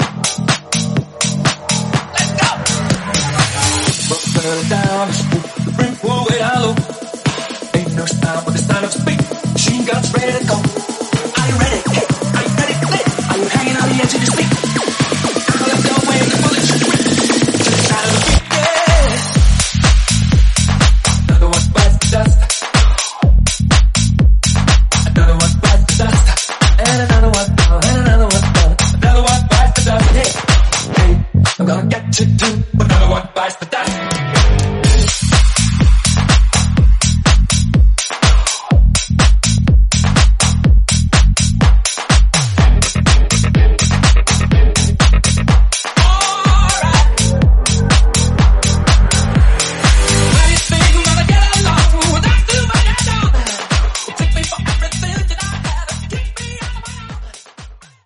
Genre: EDM
Clean BPM: 143 Time